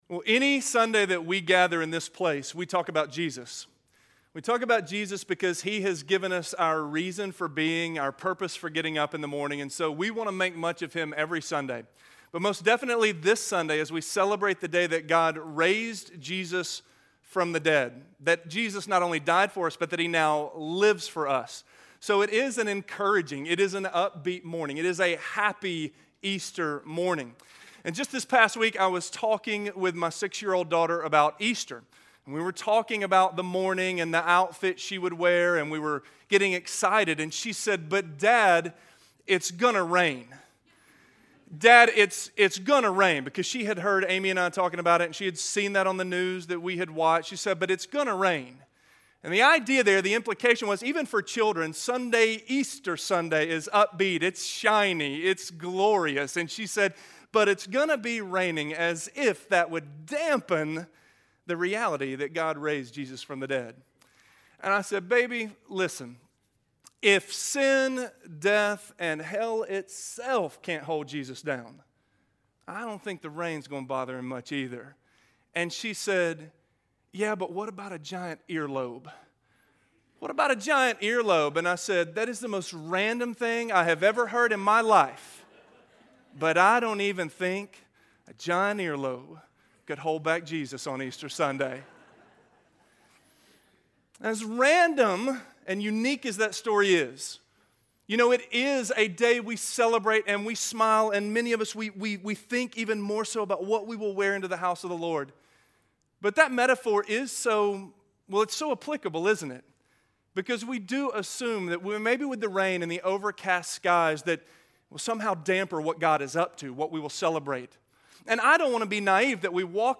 Peace Be with You - Sermon - Avenue South